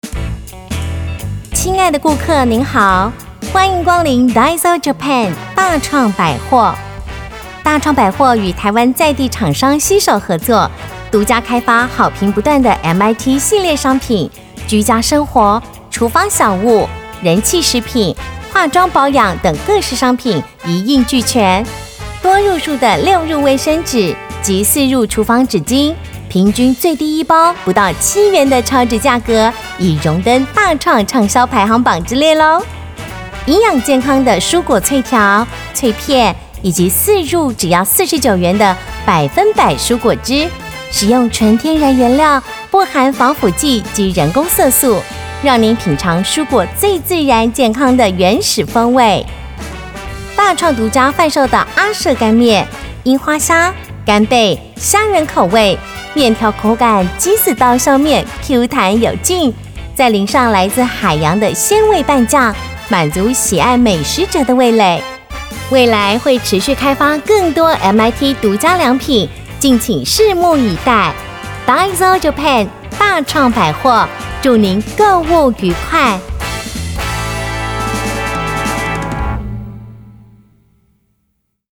女性配音員
門市—甜蜜又熱鬧：大創百貨
✔ 聲線沉穩柔和、極具親和力，適合廣告、政府宣導、公部門簡報與品牌行銷影片。
門市—甜蜜又熱鬧：大創百貨.mp3